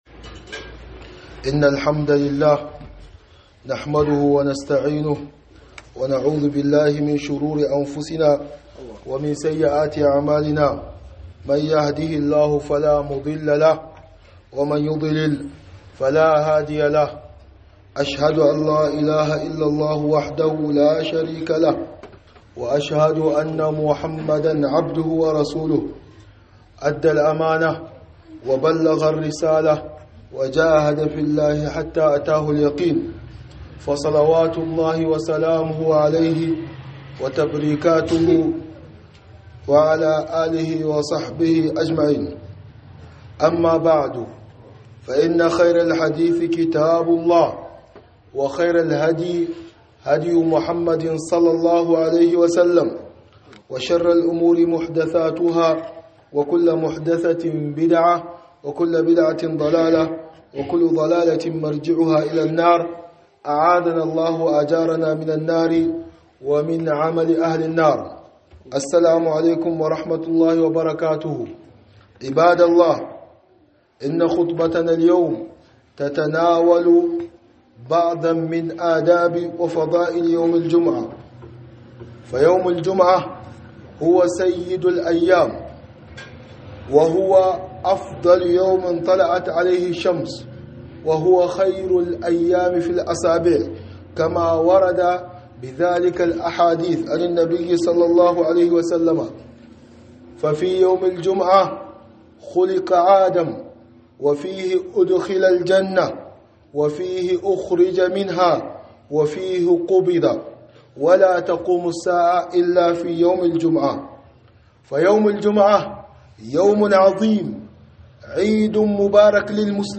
خطبة بعنوان_ من آداب يوم الجمعة وفضائله(2)